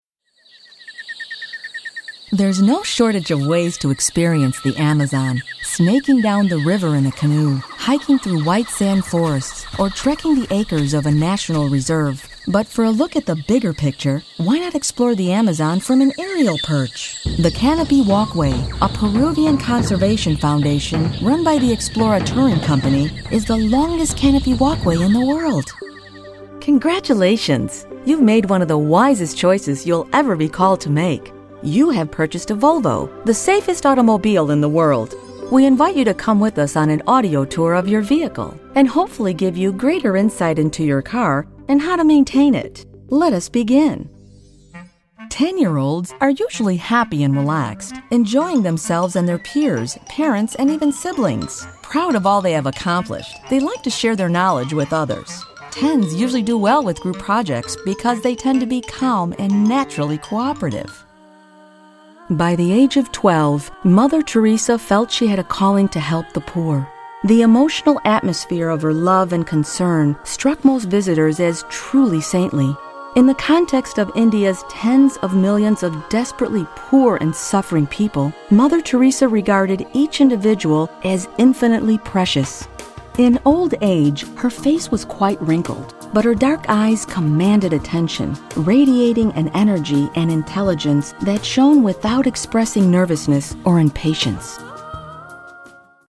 Her voice is natural, believable and engaging, perfect for a broad range of projects. Her voice is versatile, it can be friendly, fun, fresh, approachable, sincere, strong, intimate and sensual.
believable,honest,confident, warm,professional,smooth, sophisticated,fun,friendly, upbeat,trustworthy mom,sassy,sultry
middle west
Sprechprobe: eLearning (Muttersprache):